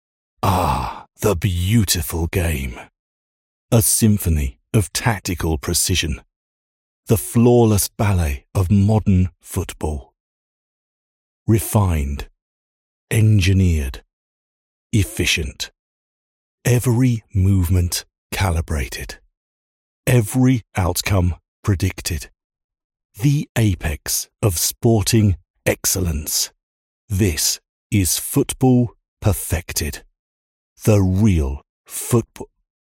British English VO from London but based in glorious Yorkshire
Inspirational tongue-in-cheek ending